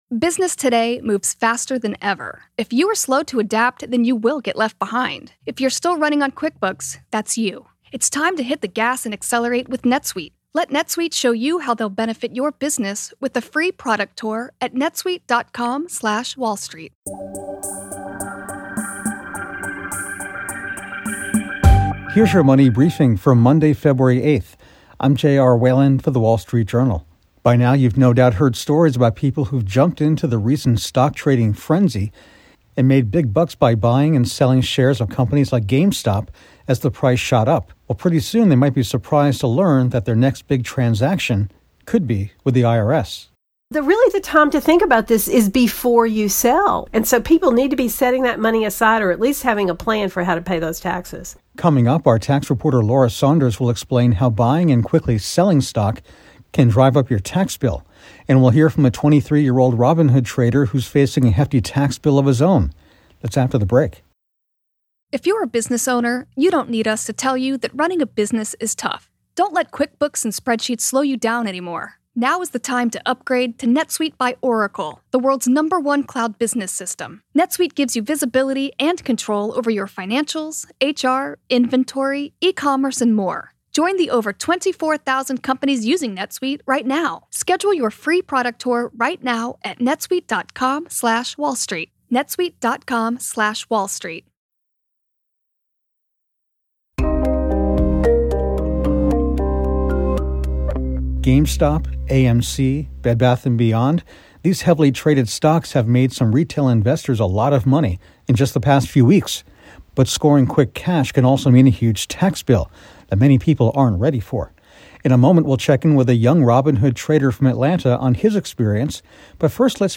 Plus, a young trader from Atlanta discusses his recent experience on Robinhood.